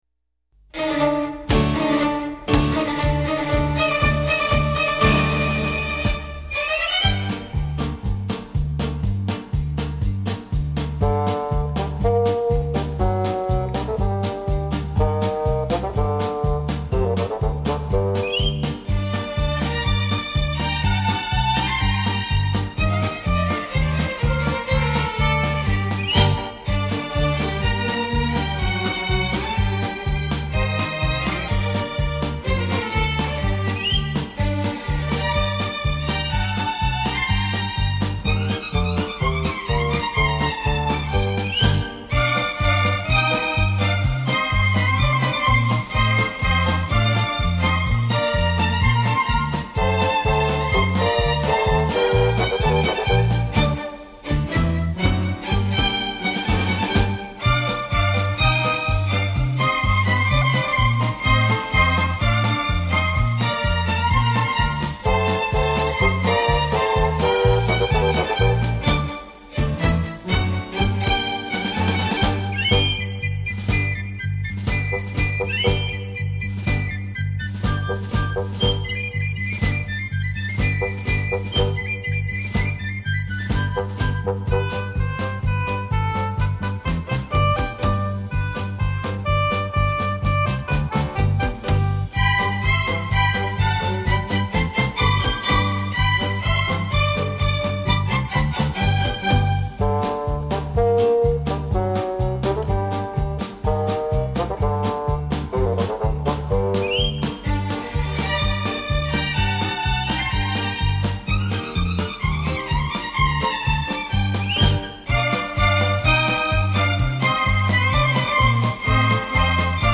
幽秘的旋律轻轻飘落床前，如梦如幻的音符掠过心湖，宁静夜晚音乐是梦的天堂。